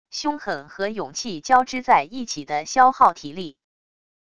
凶狠和勇气交织在一起的消耗体力wav音频